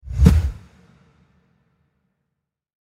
Swoosh Sound Effect – Fast Whoosh With Reverb
Description: Swoosh sound effect – fast whoosh with reverb. Ideal for cinematic edits, scene changes, intros, outros, and motion graphics.
Genres: Sound Effects
Swoosh-sound-effect-fast-whoosh-with-reverb.mp3